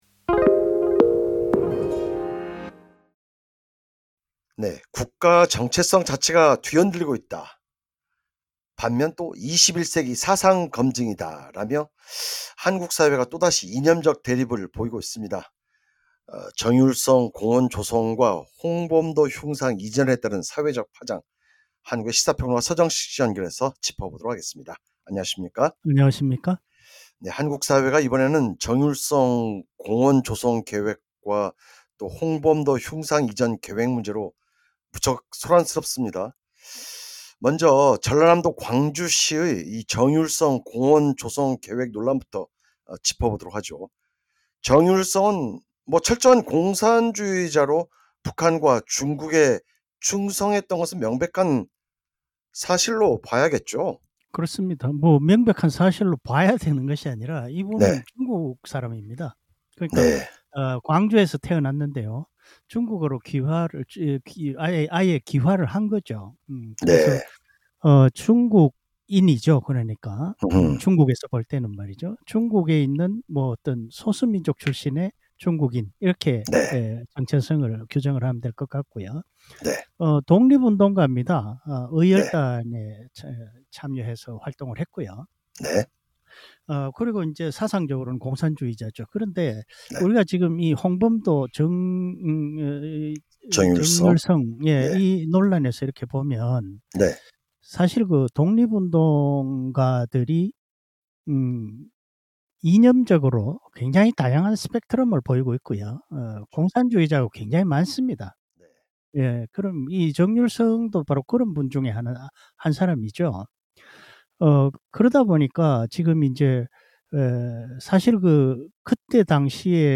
해설: 시사평론가